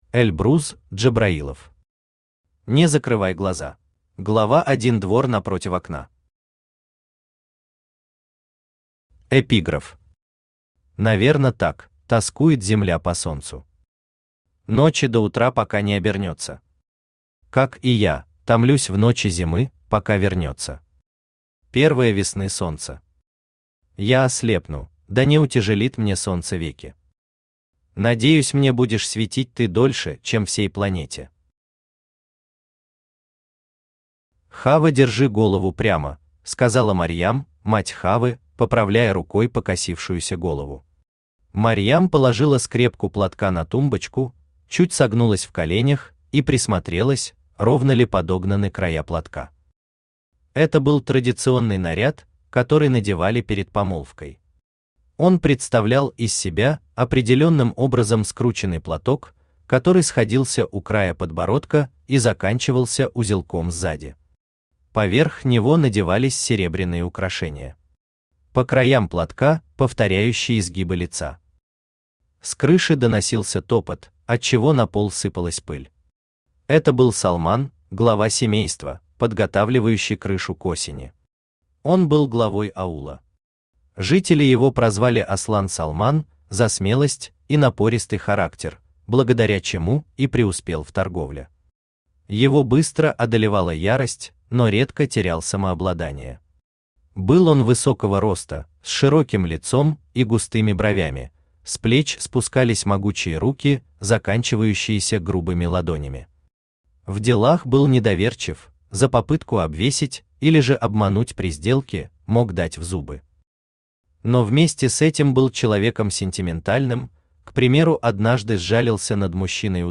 Аудиокнига Не закрывай глаза | Библиотека аудиокниг
Aудиокнига Не закрывай глаза Автор Эльбрус Баграмович Джабраилов Читает аудиокнигу Авточтец ЛитРес.